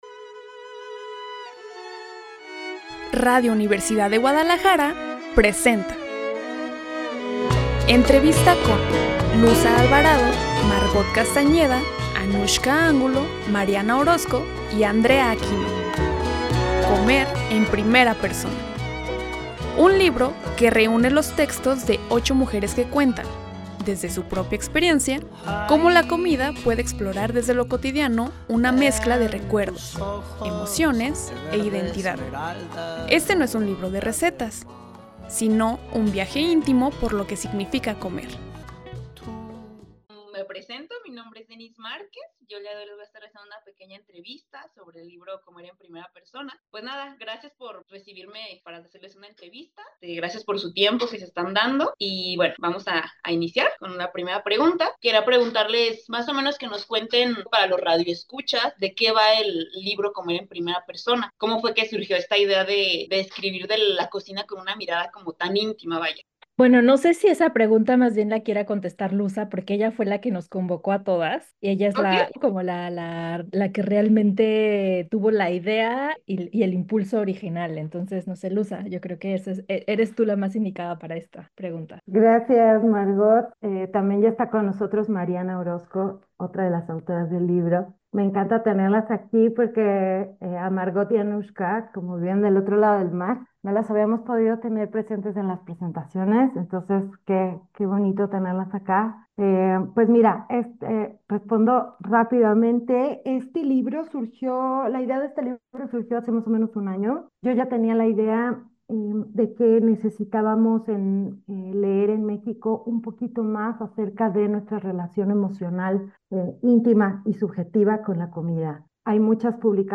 Cobertura Fil 2025 - Entrevista Libro: Comer en primera Persona - Mi. 26 Nov 2025